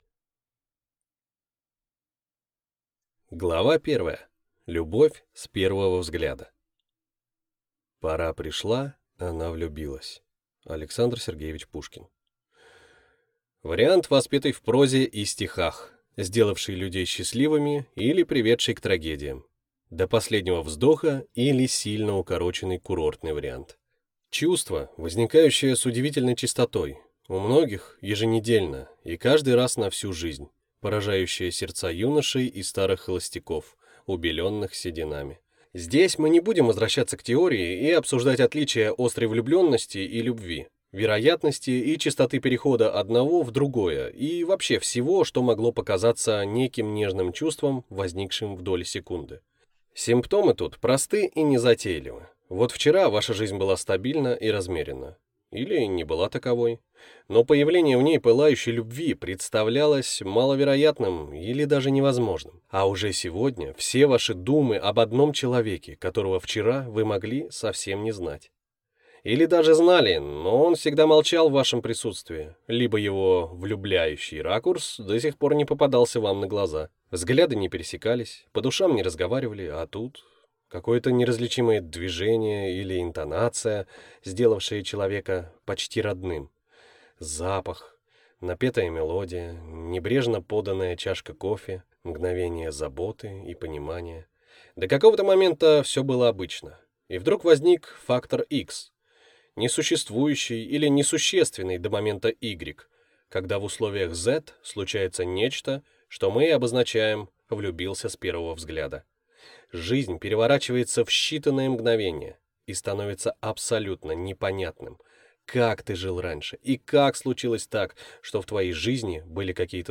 Аудиокнига Любовь, похожая на стон | Библиотека аудиокниг